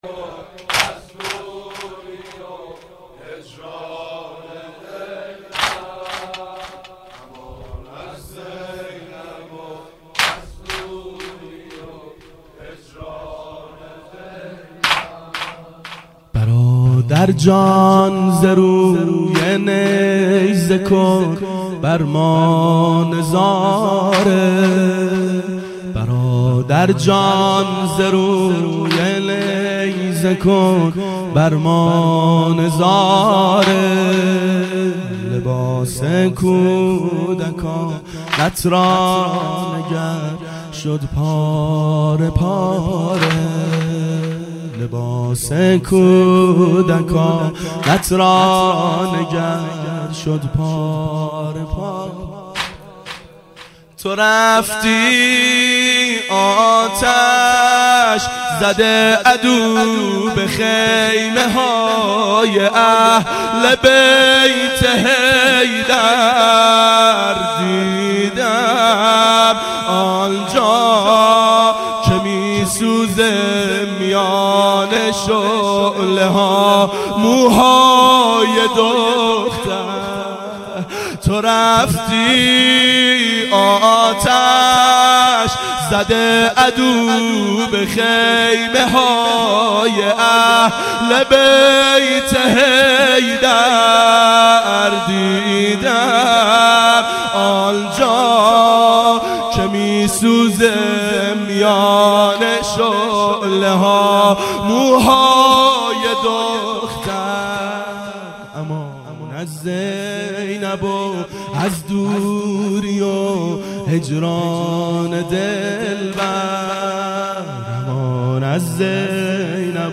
ظهر اربعین سال 1389 محفل شیفتگان حضرت رقیه سلام الله علیها